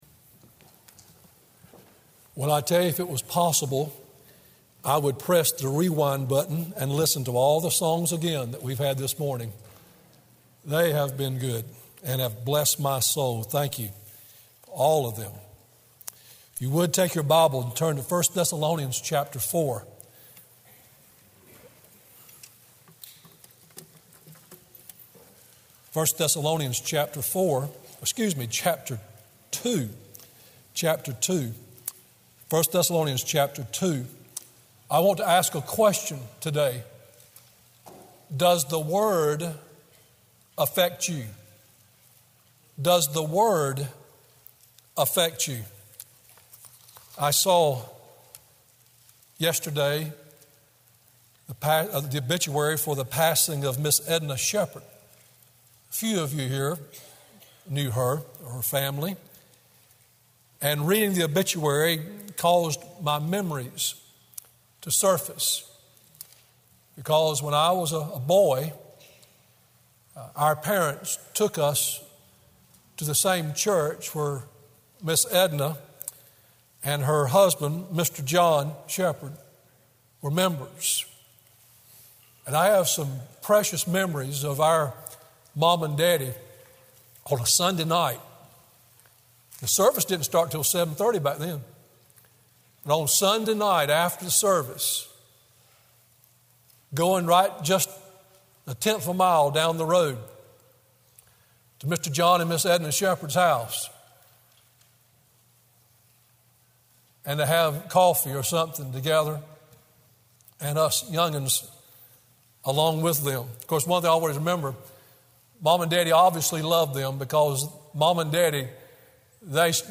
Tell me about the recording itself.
Morning Worship1st Thessalonians 2:13